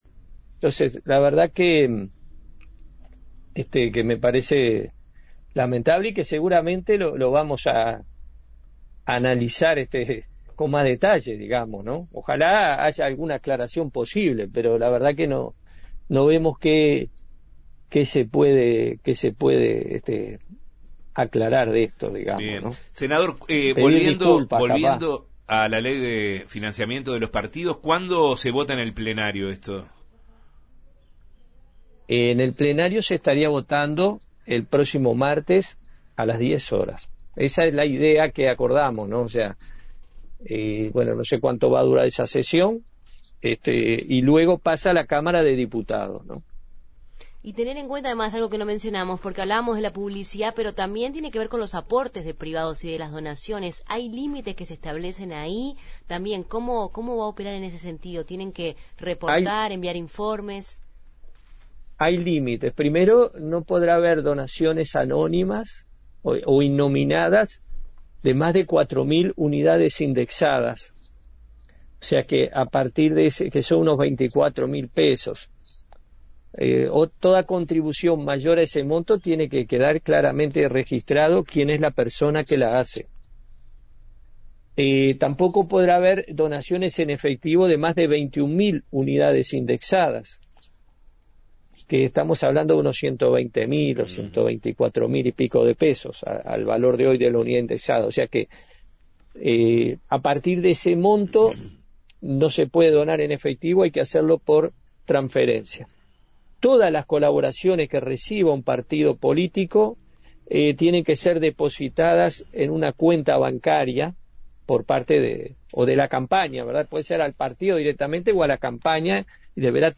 ENTREVISTA-SENADOR-JOSE-NUNES.mp3